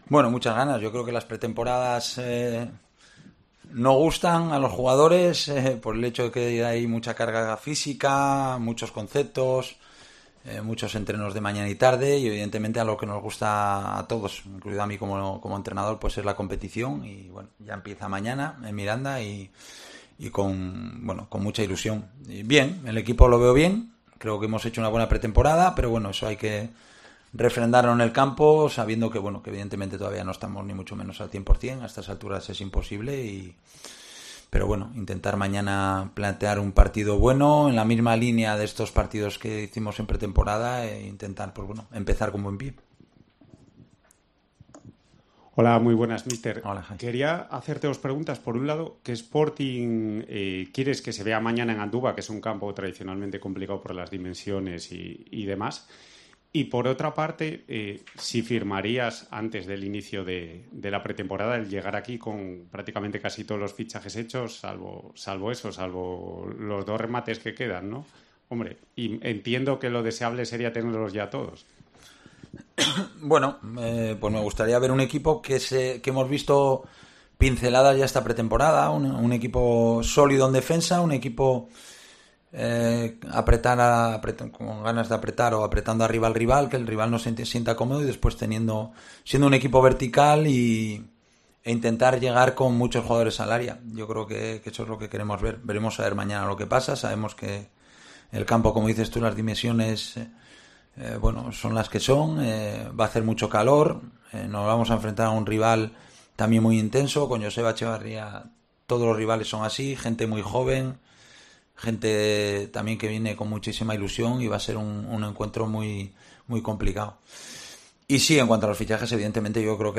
Rueda de prensa de Abelardo (previa Mirandés)